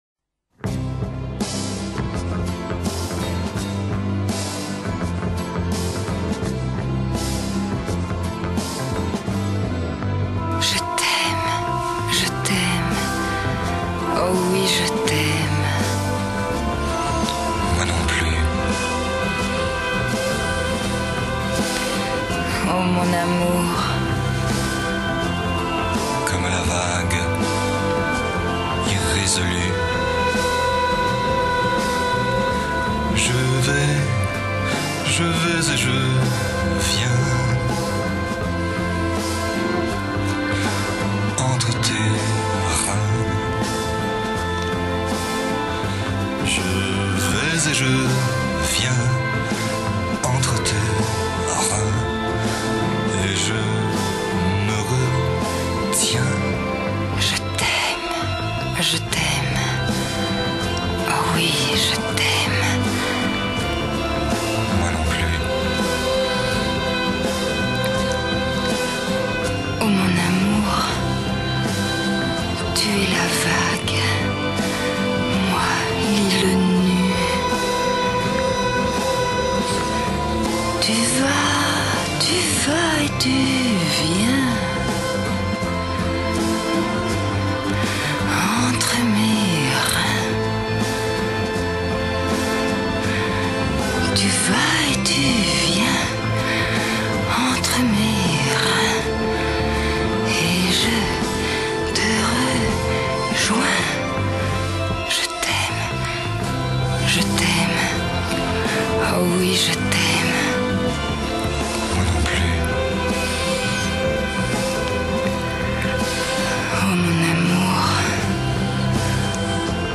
Genre: French Chanson, Ballad